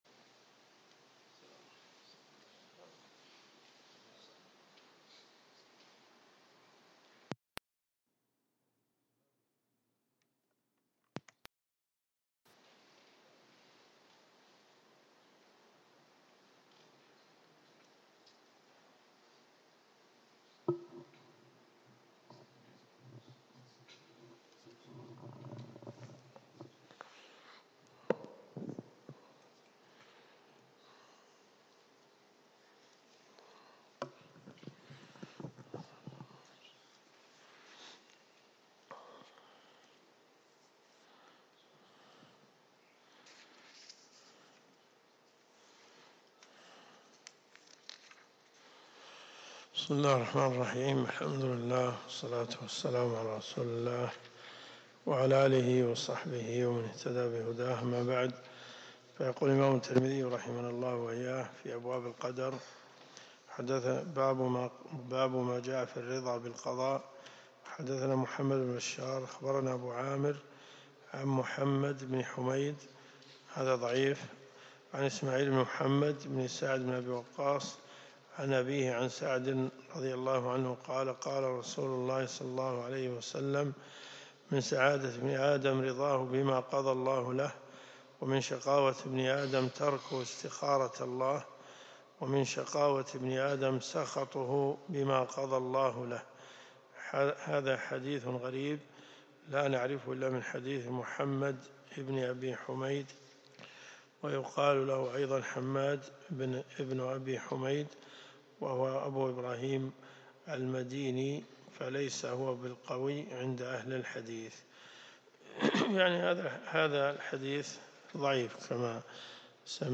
الرئيسية الكتب المسموعة [ قسم الحديث ] > جامع الترمذي .